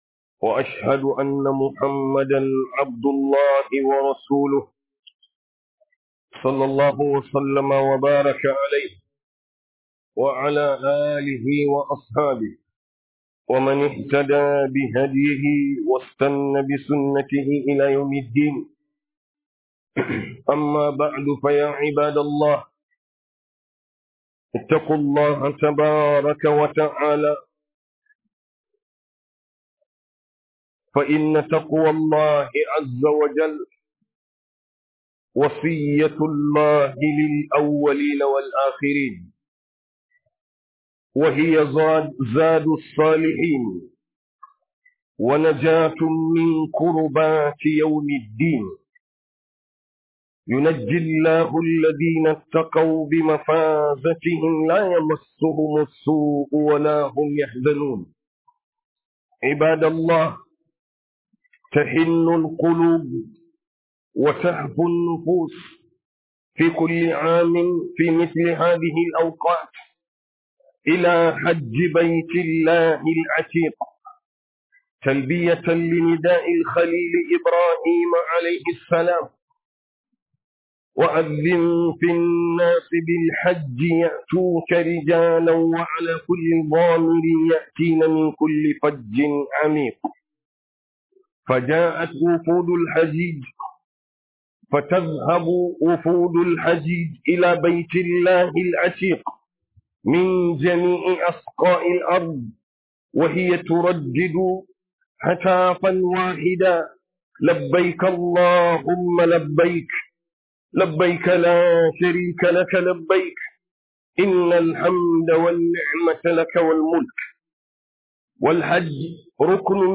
Manufofin aikin Hajji - Huduba